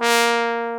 Index of /90_sSampleCDs/AKAI S-Series CD-ROM Sound Library VOL-2/1095 TROMBON